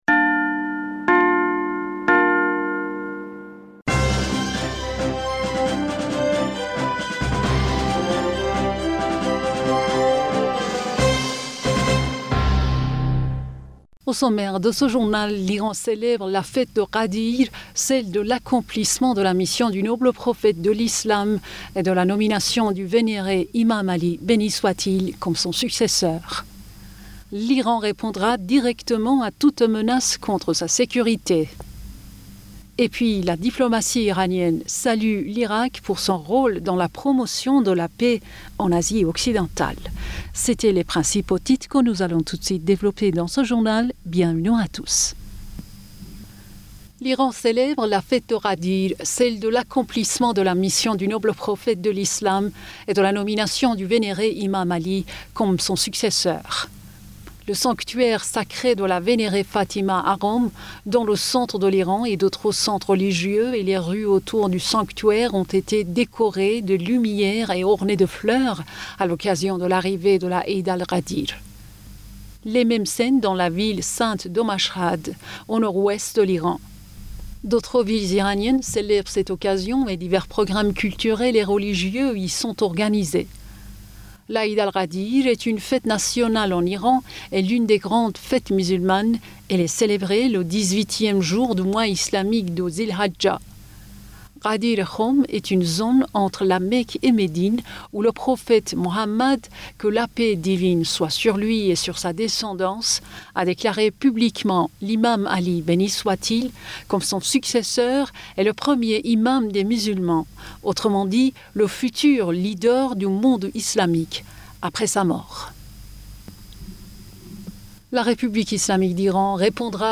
Bulletin d'information Du 18 Julliet